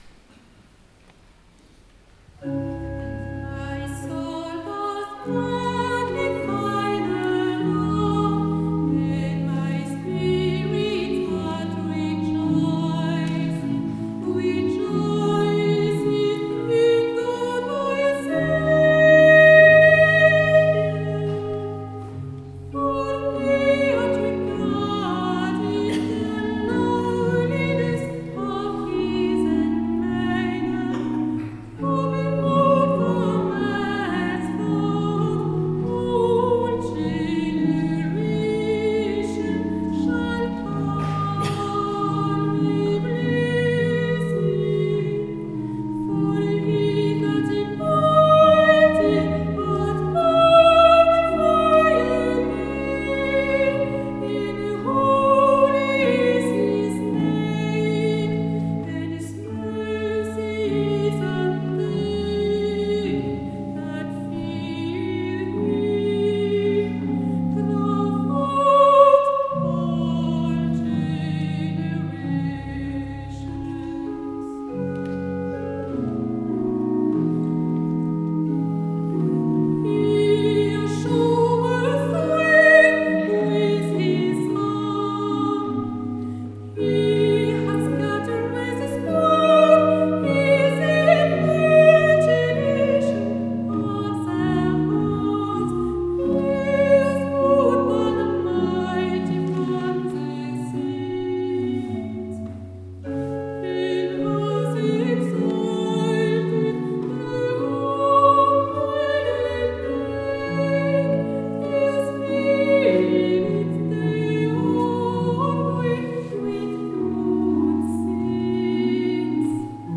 Concerts de l'Atelier 95 les 6 et 7 février 2016
Découverte des motets anglais du XVIème au XXème siècle